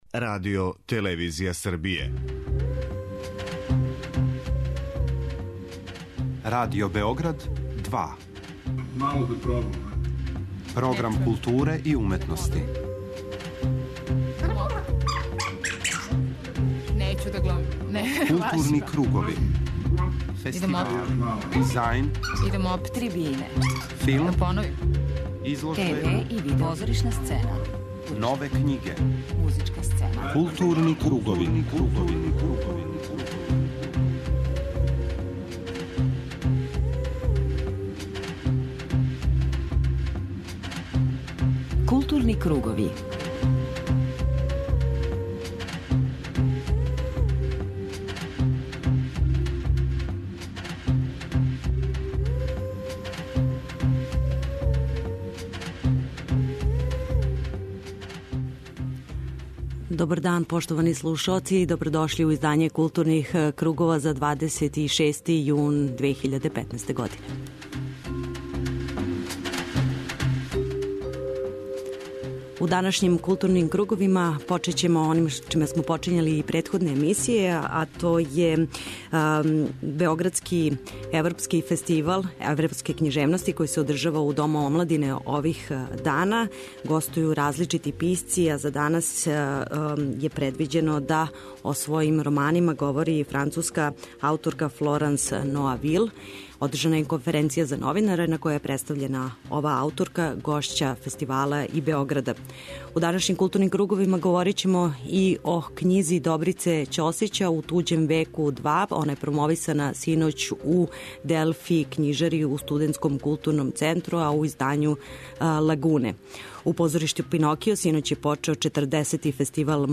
преузми : 54.25 MB Културни кругови Autor: Група аутора Централна културно-уметничка емисија Радио Београда 2.